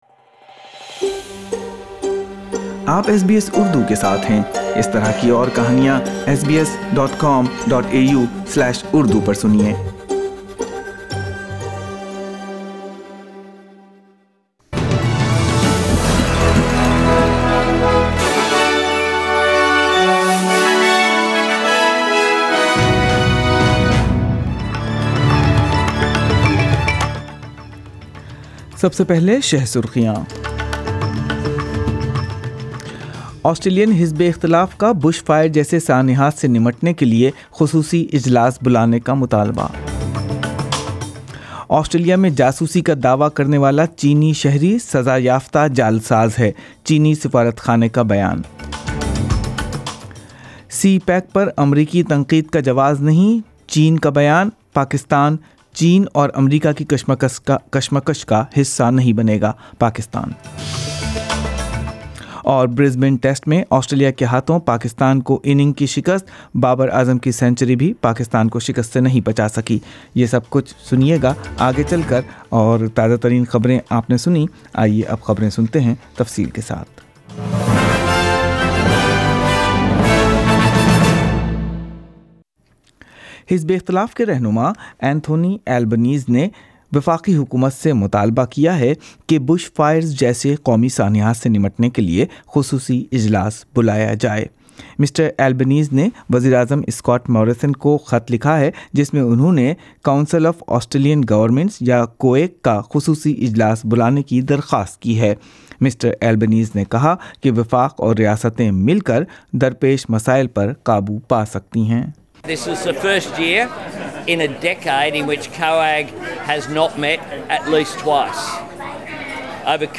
اردو خبریں پچیس نومبر ۲۰۱۹